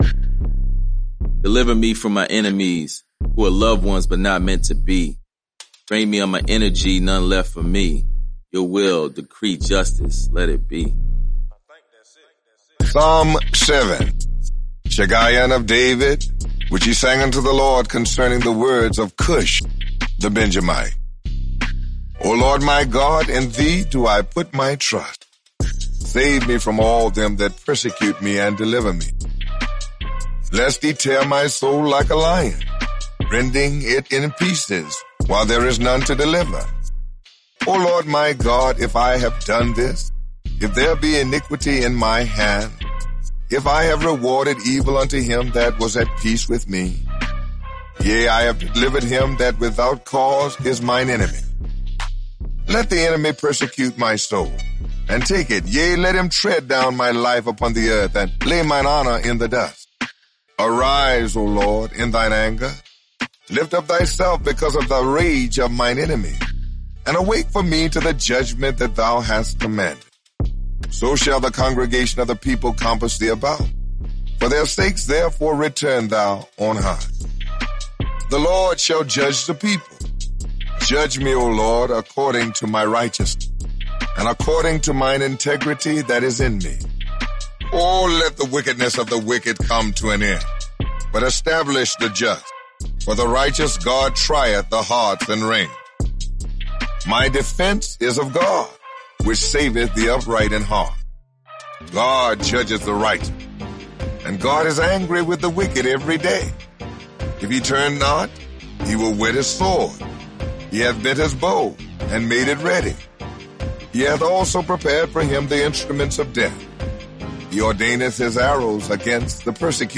Experience the Psalms in a fresh new way! This narrated devotional provides encouraging insight over original music that injects hope, faith, wisdom, inspiration, and so much more through the Psalms!